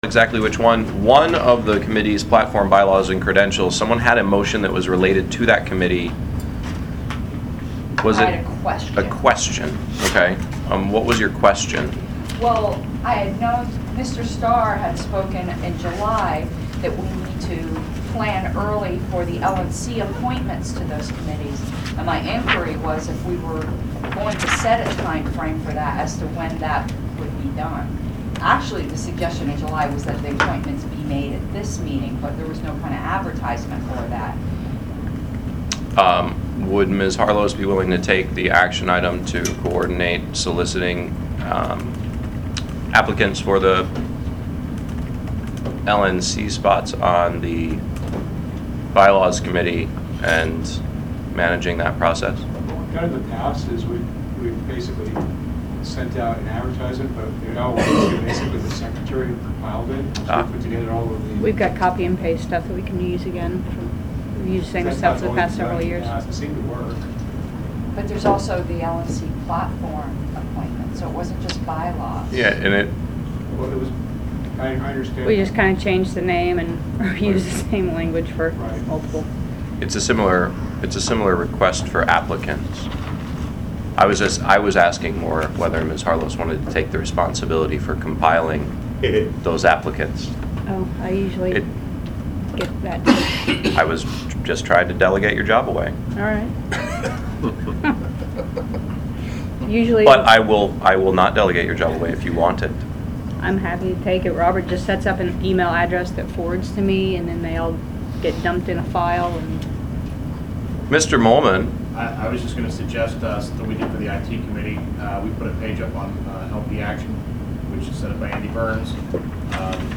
I have obtained the attached audio excerpt from the meeting, and the entire